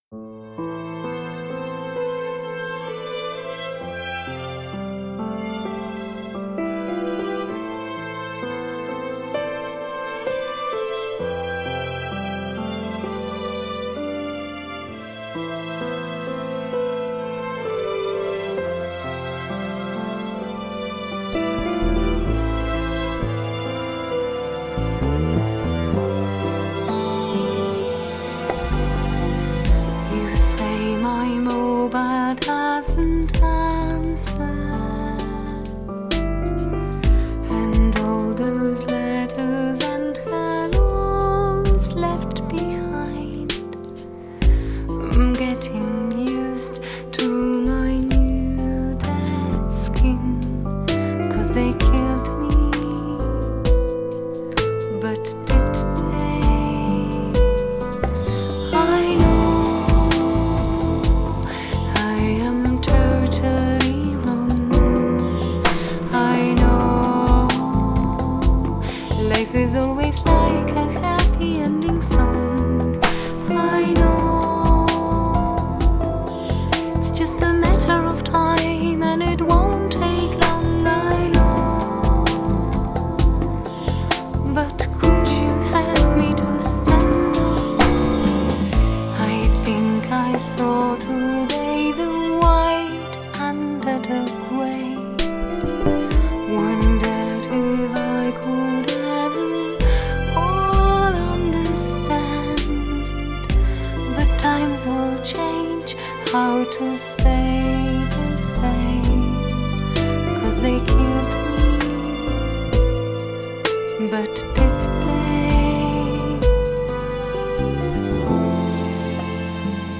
Multilingual Jazz singer